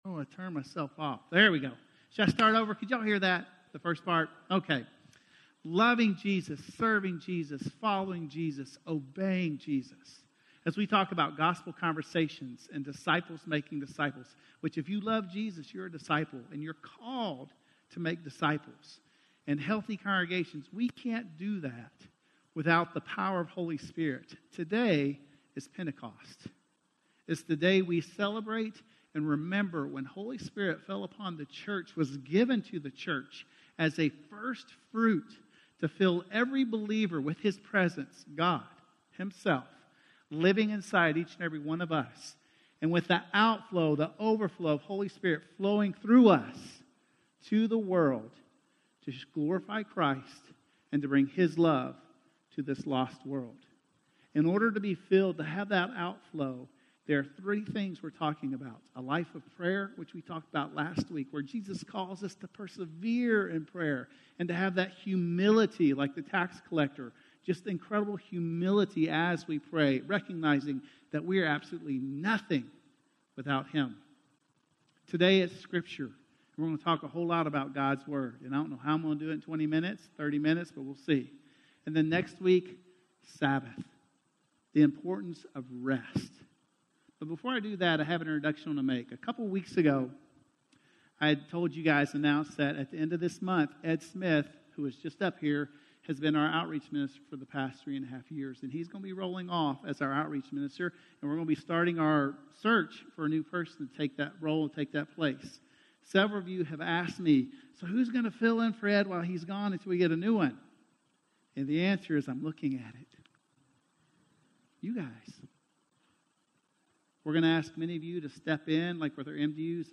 Sermons - Woodbine